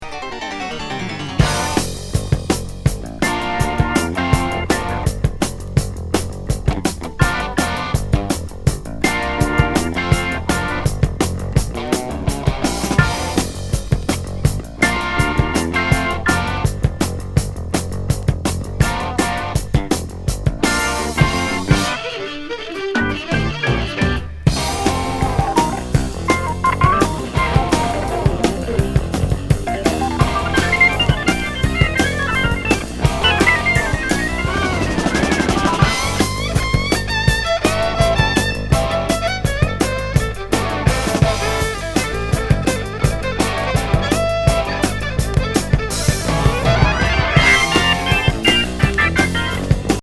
エレクトリック・ヴァイオリン、ヴィオラ